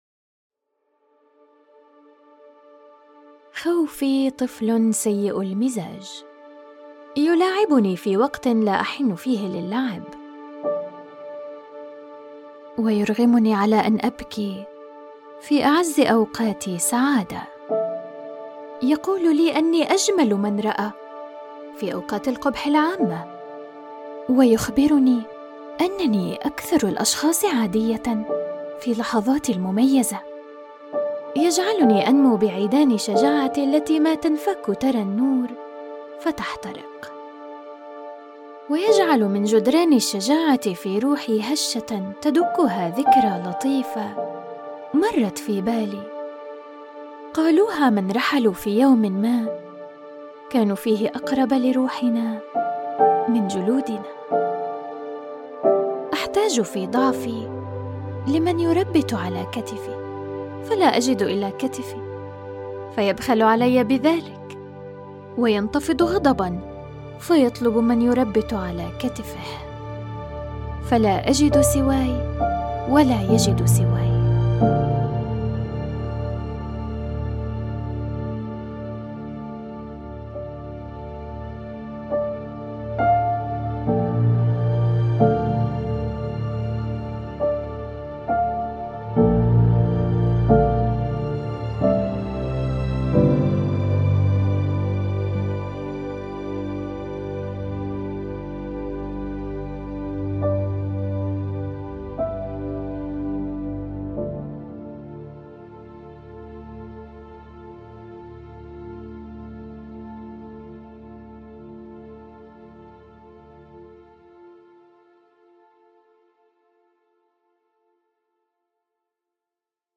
Audio Book Voice Over Narrators
Adult (30-50)